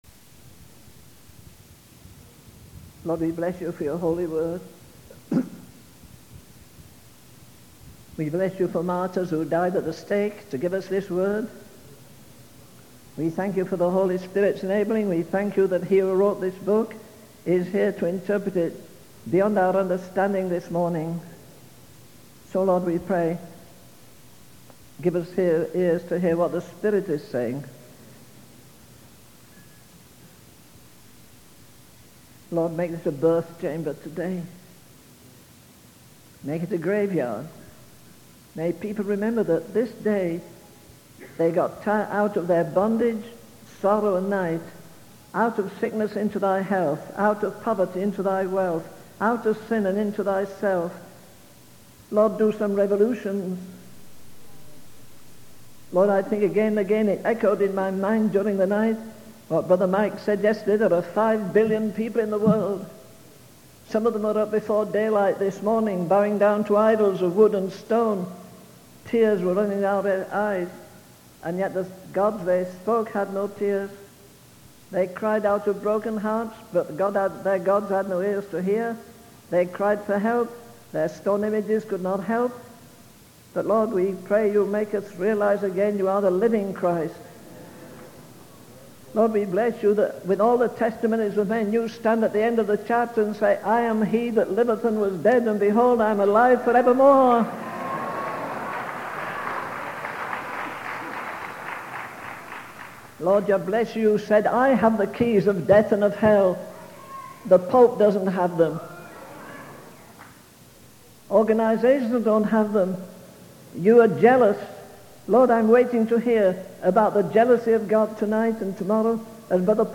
In this sermon, the preacher begins by expressing gratitude for the holy word of God and the martyrs who sacrificed their lives for it.